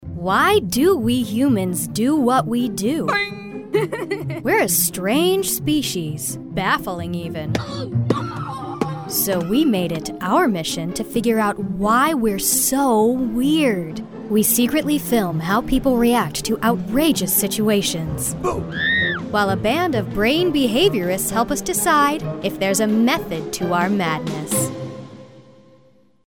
Promos
I have a wonderfully weird, bouncy voice that has inspired many a collaborator to think about their works in entirely new lights.
-StudioBricks double-walled insulated vocal booth